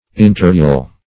Search Result for " interhyal" : The Collaborative International Dictionary of English v.0.48: Interhyal \In`ter*hy"al\, a. [Inter- + the Greek letter ?.]